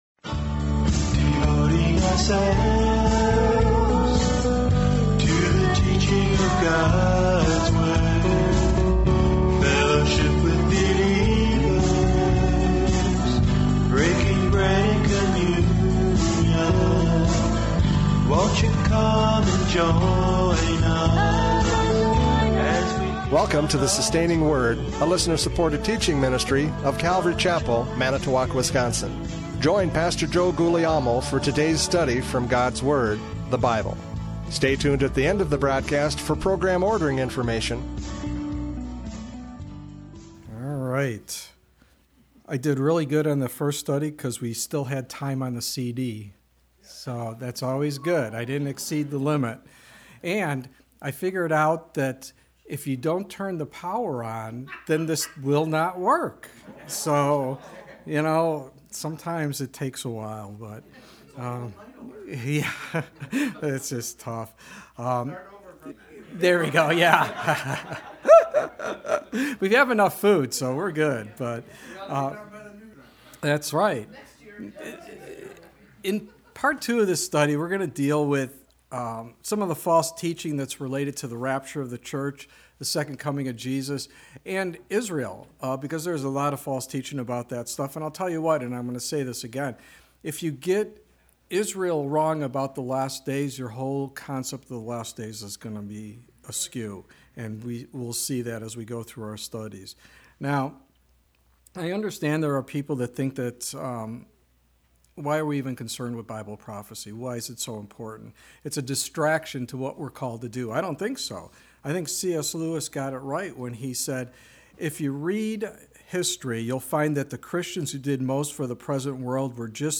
Radio Studies Service Type: Radio Programs « Prophecy Update 2024 Convergence!